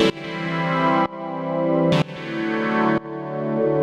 GnS_Pad-dbx1:2_125-E.wav